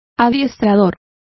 Complete with pronunciation of the translation of trainers.